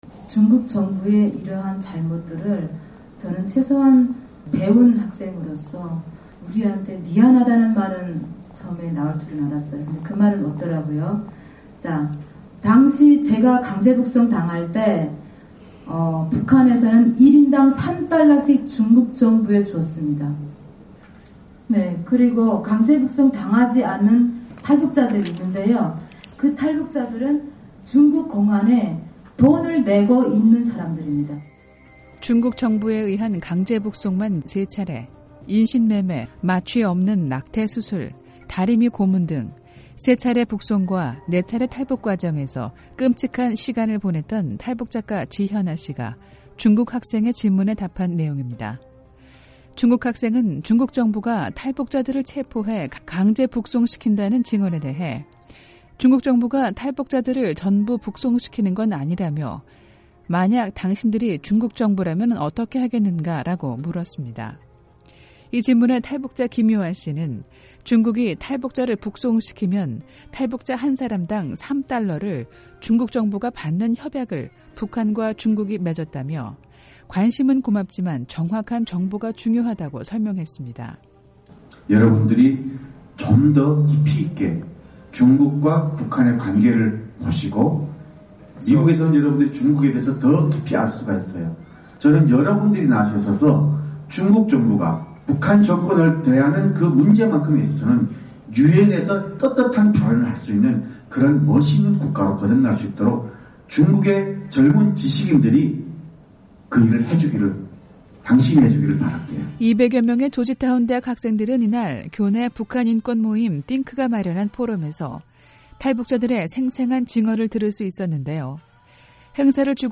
[뉴스 풍경 오디오 듣기] 미국 조지타운대학서 탈북자들 인권실태 증언